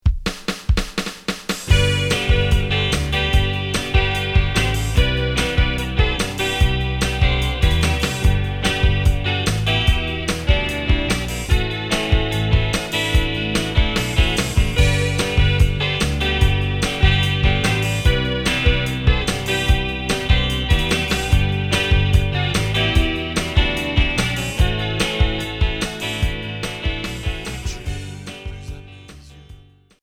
Pop indé